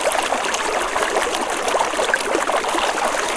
fountain-2.ogg.mp3